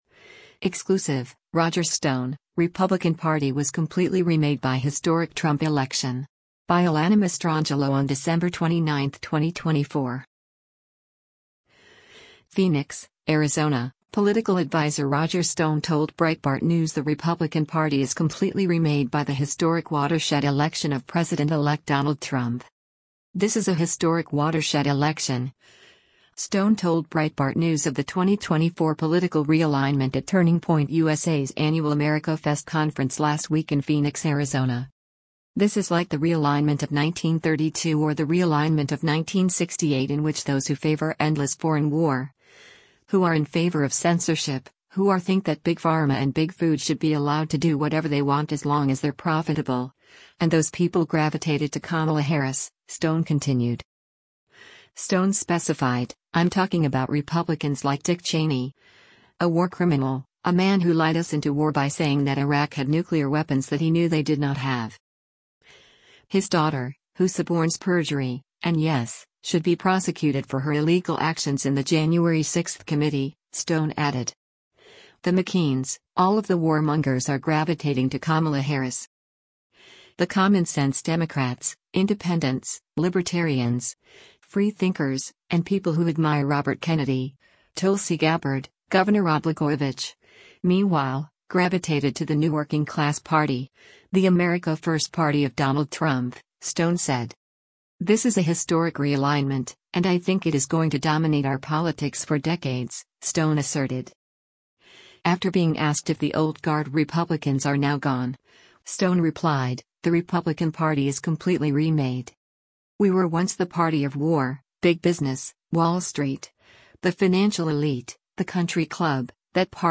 “This is a historic watershed election,” Stone told Breitbart News of the 2024 political realignment at Turning Point USA’s annual AmericaFest conference last week in Phoenix, Arizona.